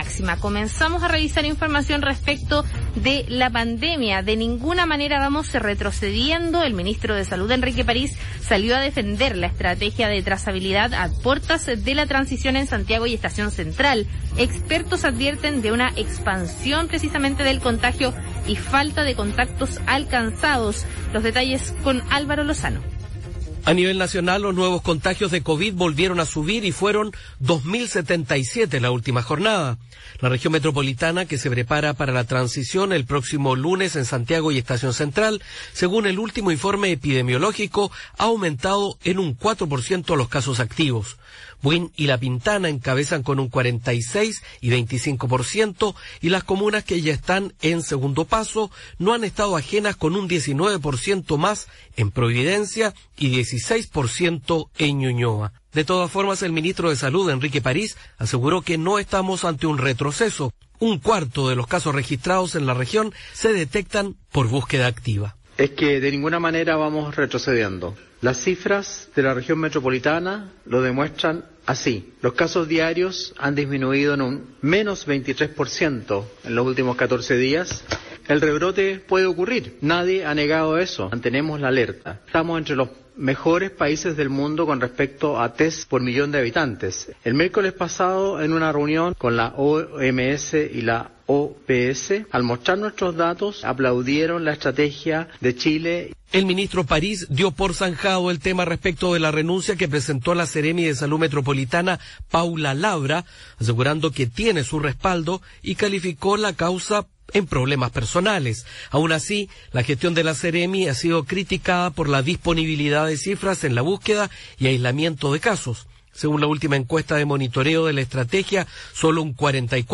Habla: -Enrique Paris, ministro de Salud.